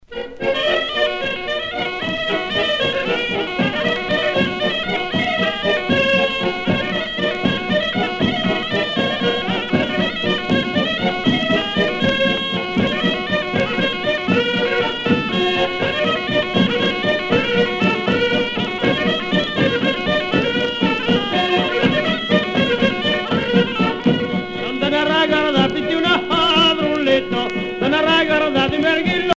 danse : bourree
Genre brève